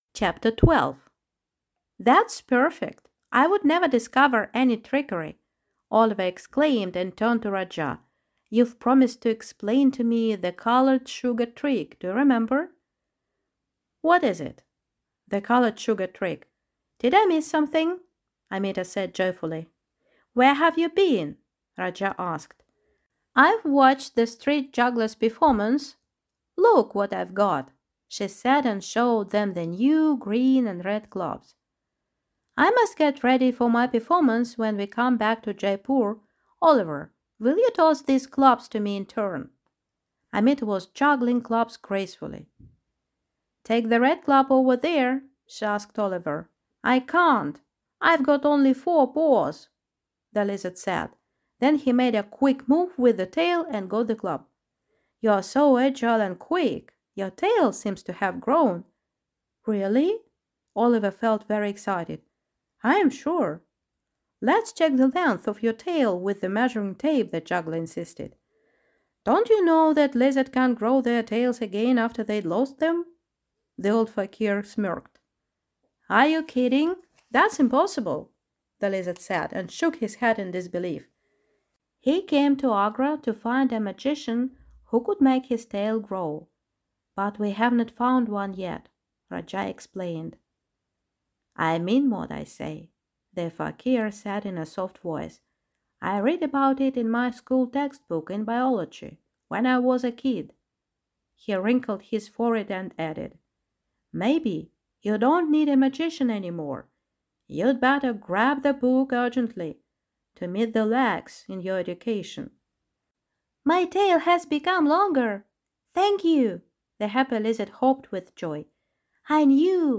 Аудиокнига A Peacock Song. Part Three | Библиотека аудиокниг